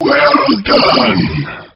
Line of Bubbler in Diddy Kong Racing.